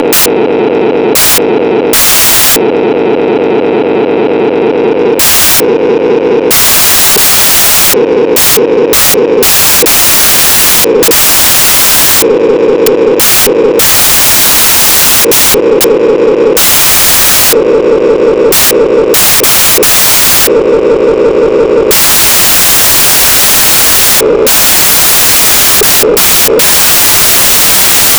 The ADSMK2 is an auto diesel train sound board that has been designed to mimic diesel engines it is a non dcc circuit board and has the advantage of being able to be adjusted to sound like different engines with just one adjustment. you can make it sound from a whining supercharged diesel to a ruff old growler.
ACCELERATING